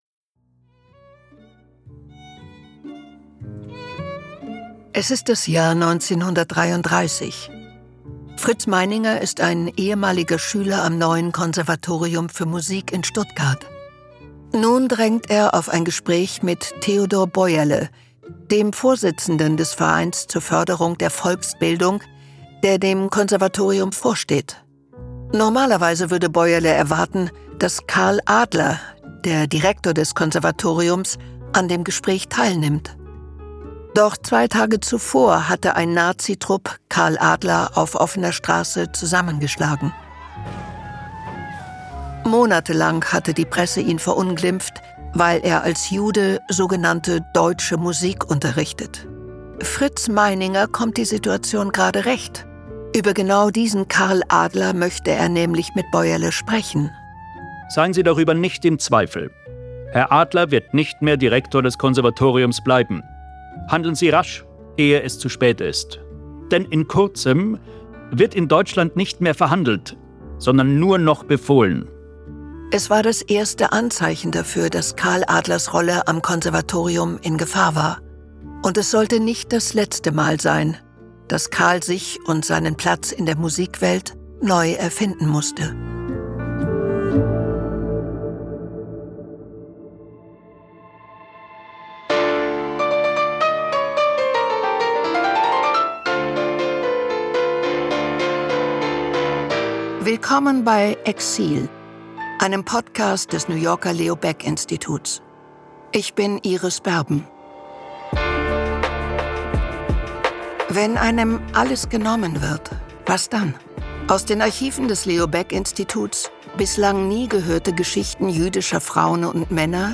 Sprecherin: Iris Berben